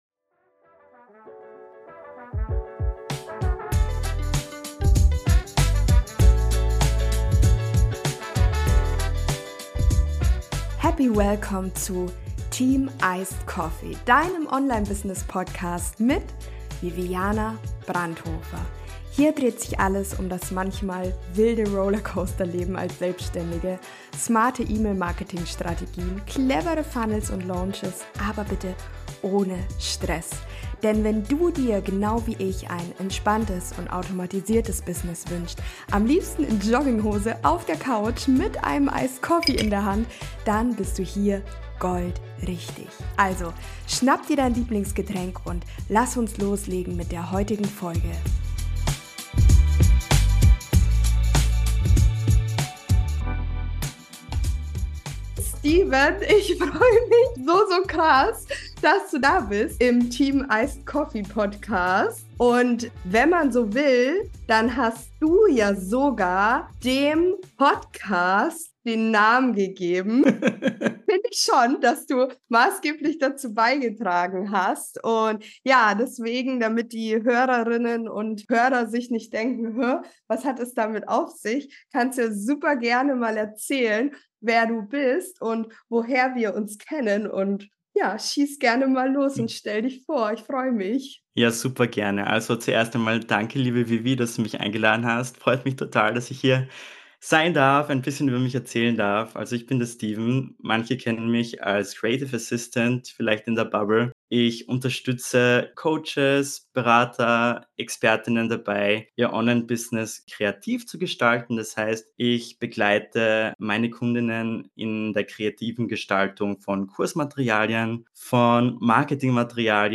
Smarte Arbeit durch Künstliche Intelligenz: Interview